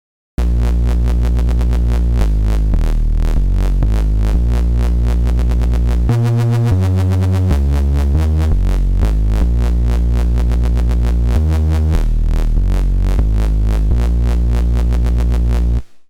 As used in dubstep/grime.
I used FM and detune for the above.
GrimeyMod.mp3